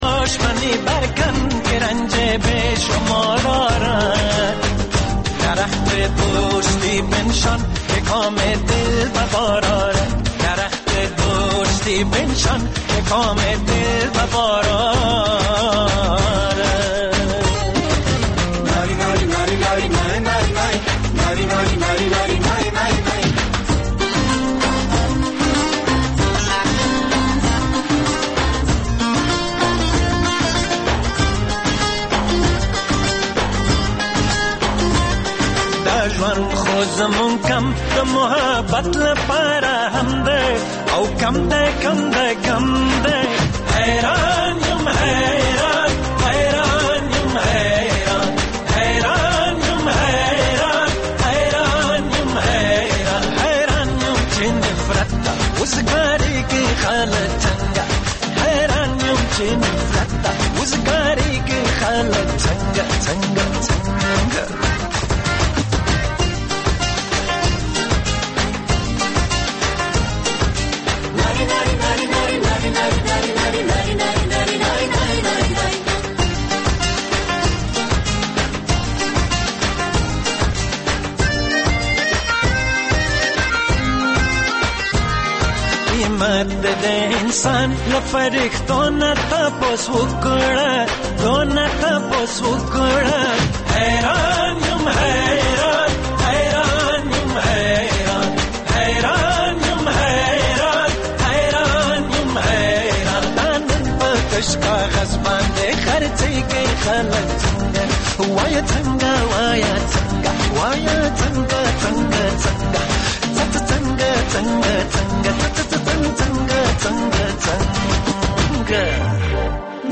د سندرو مېلمانه ( موسیقي)